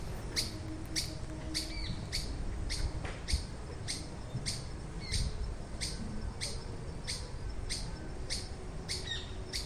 Myiodynastes maculatus solitarius
Nombre en español: Benteveo Rayado
Localidad o área protegida: Gran Buenos Aires Norte
Condición: Silvestre
Certeza: Observada, Vocalización Grabada